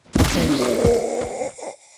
enemy_small_defeat.ogg